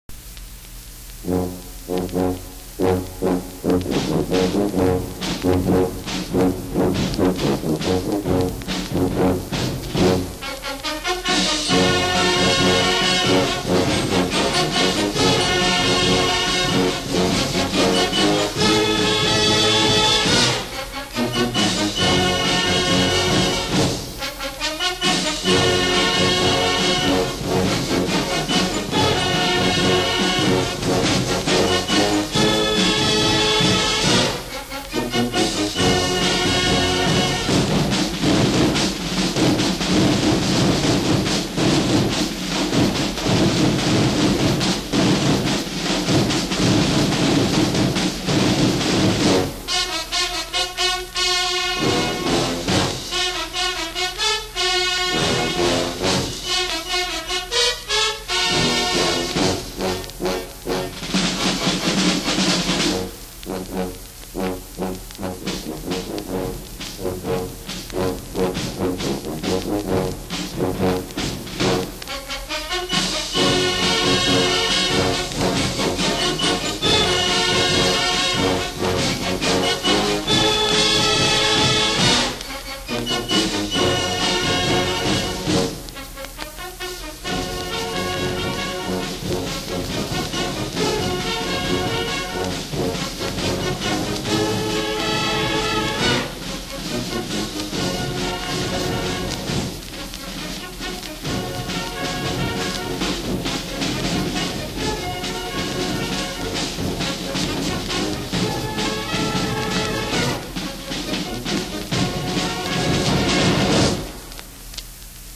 MCC Marching Band